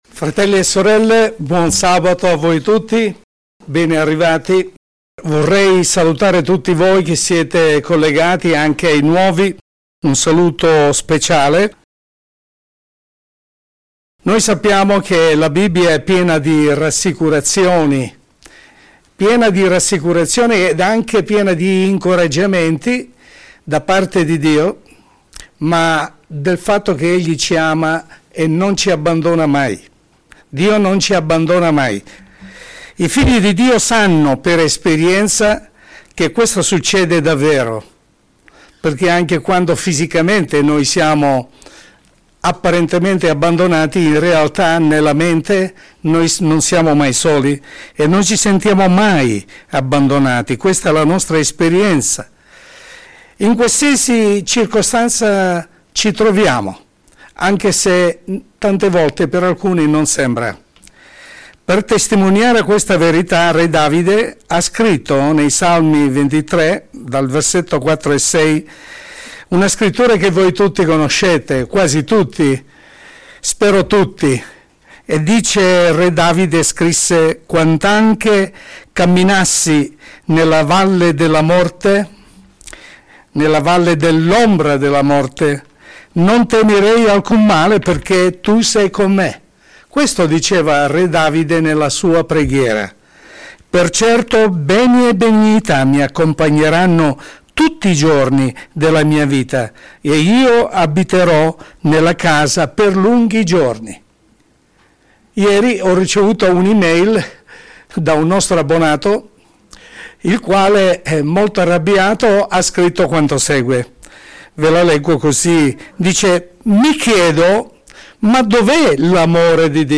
Sermone pastorale